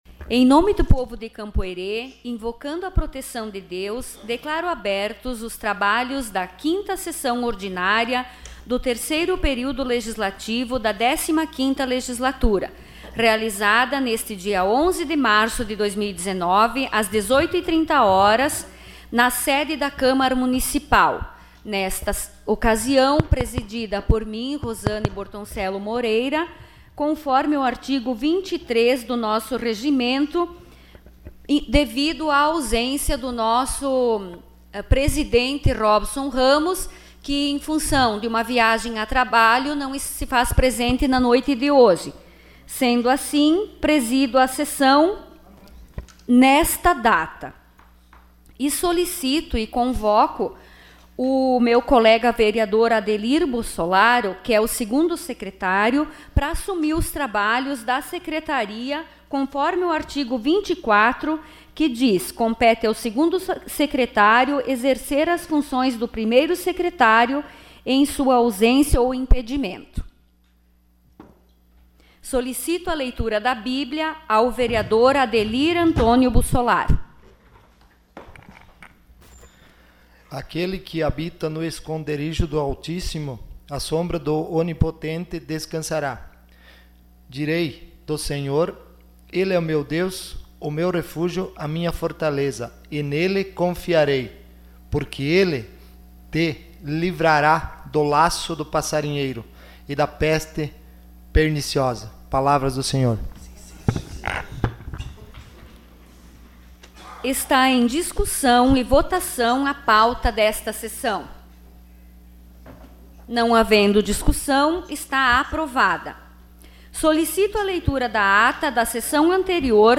Sessão Ordinária dia 11 de março de 2019.